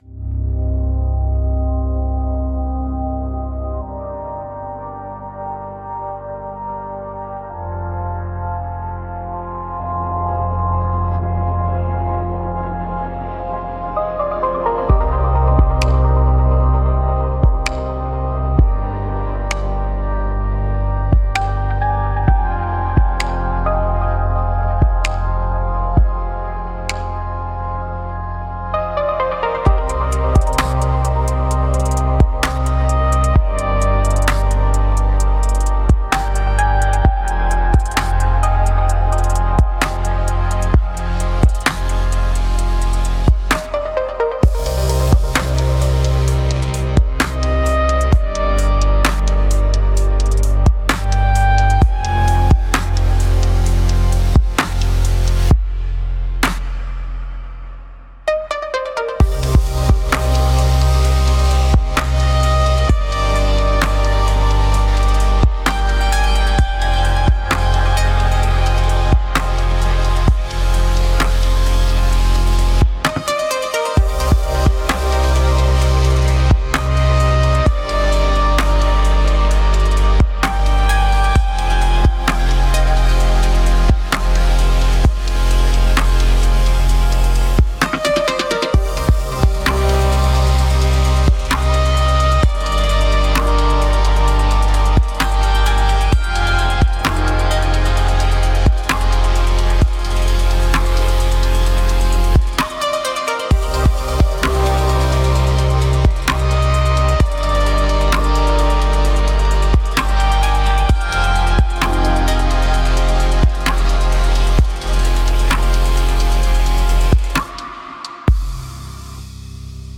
Ai instrumental